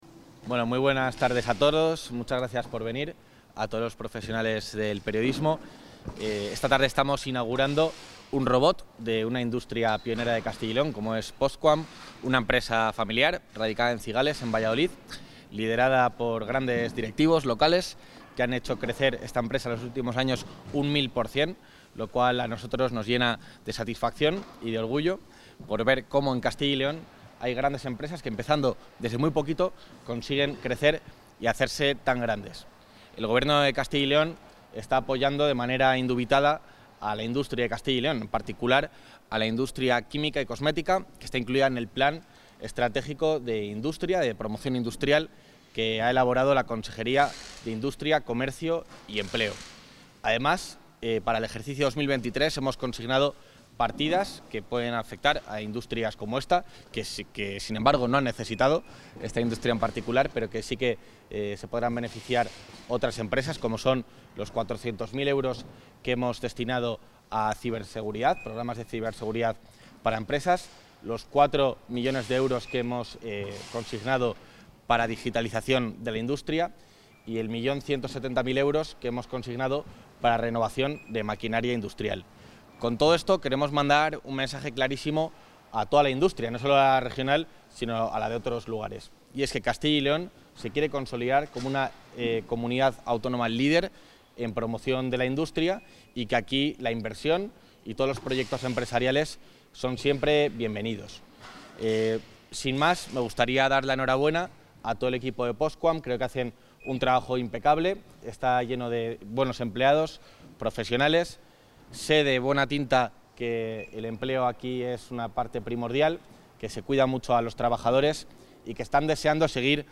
Intervención del vicepresidente de la Junta.
El vicepresidente de la Junta de Castilla y León, Juan García-Gallardo, ha presidido esta tarde la inauguración del nuevo robot que la empresa Postquam Cosmetic ha instalado en su centro estratégico de Cigales (Valladolid), con el que pretenden alcanzar el ambicioso objetivo de duplicar las ventas online en los próximos dos años.